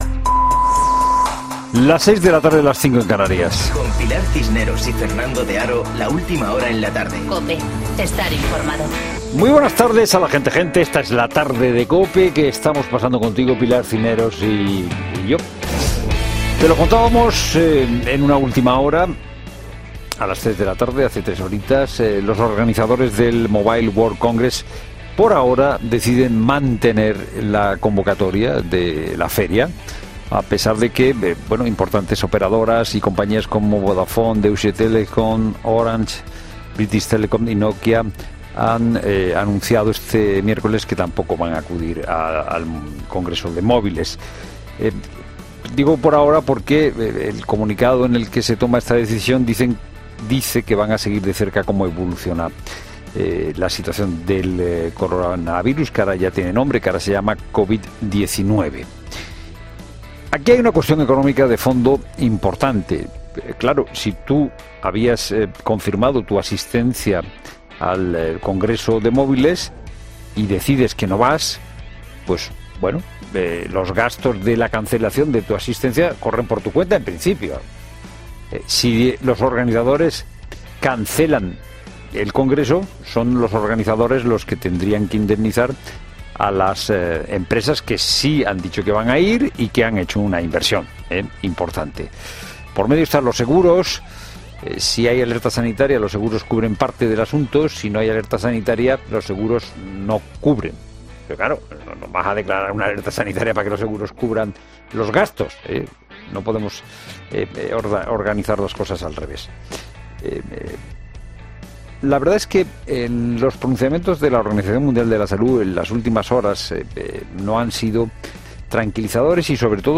Boletín de noticias COPE del 12 de febrero de 2020 a las 18.00 horas